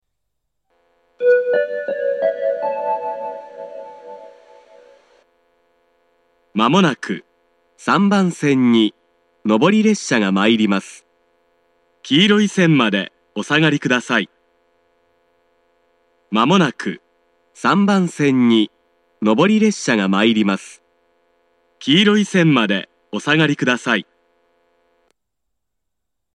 仙石型（男性）
接近放送
2番線の放送とほぼ同じで、異なるのは2番線が3番線になるくらいです。